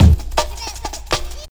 Think1 Breakbeat 32k